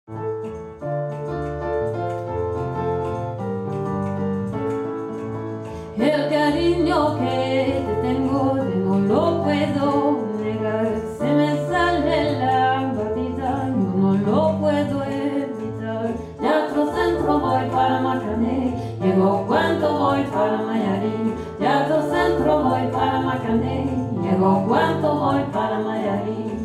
Musiques latines / africaines / bossa